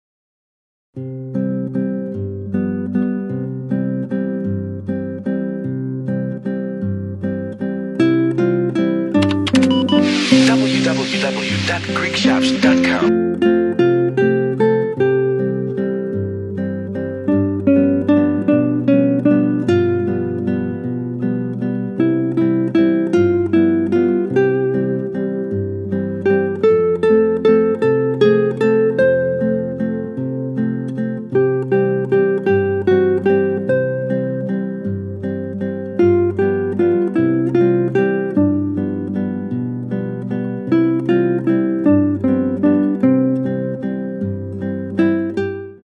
Instrumental Lullabies included on the CD: